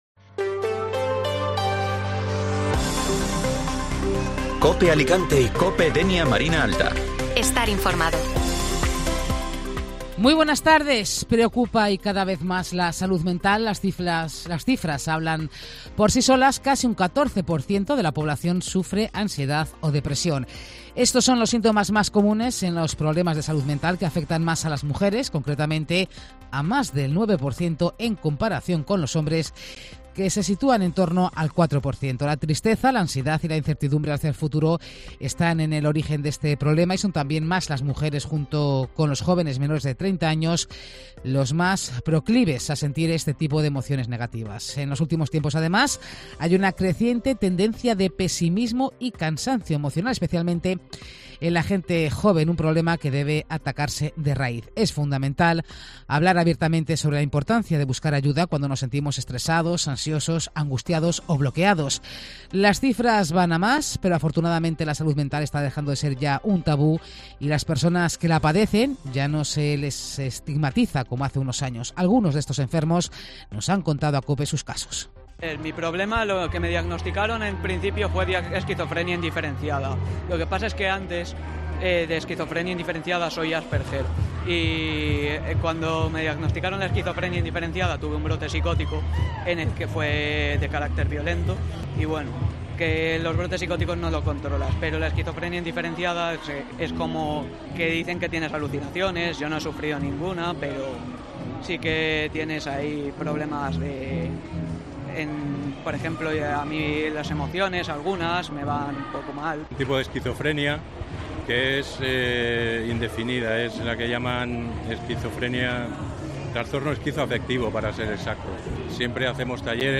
Informativo Mediodía Cope Alicante (Martes 10 de Octubre)